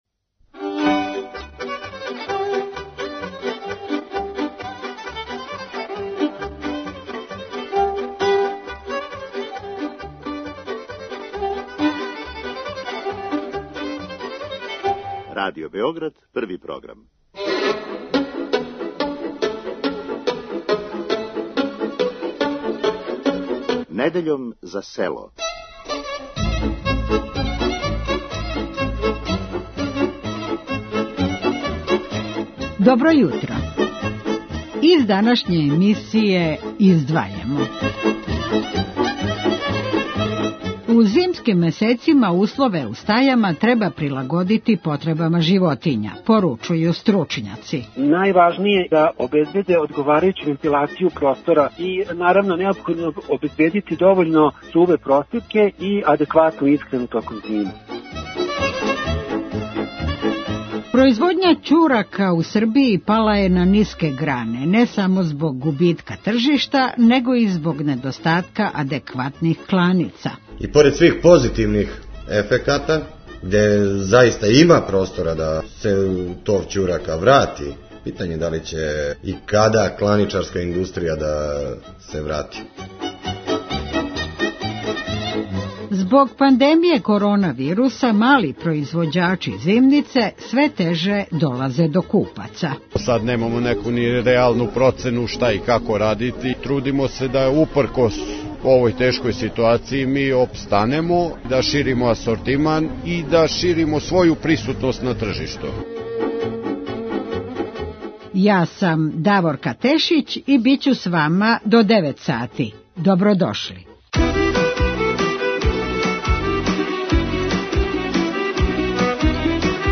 Радио Београд 1, недеља, 8,05